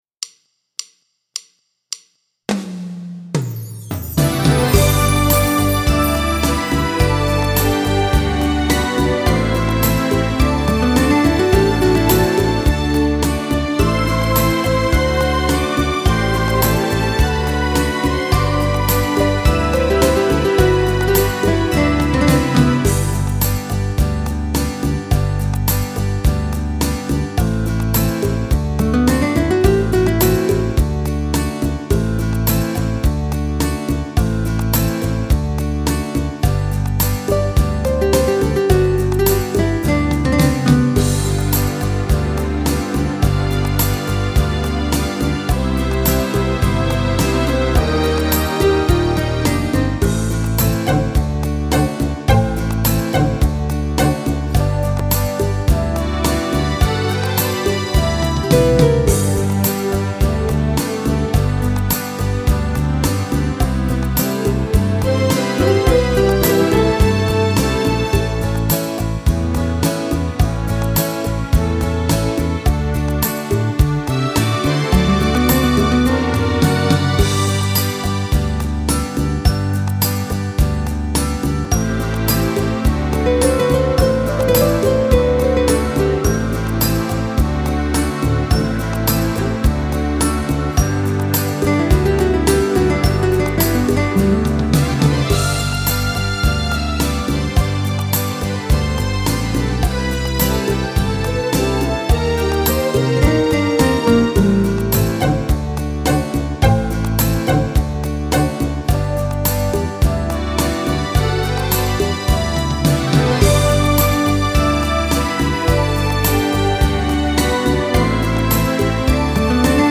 moderato